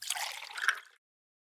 PixelPerfectionCE/assets/minecraft/sounds/item/bottle/fill4.ogg at ca8d4aeecf25d6a4cc299228cb4a1ef6ff41196e